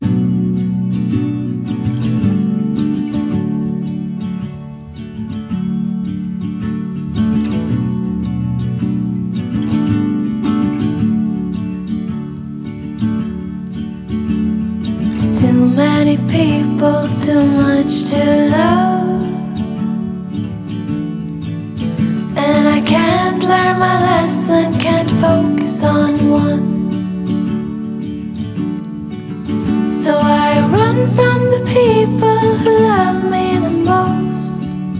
# Инди-рок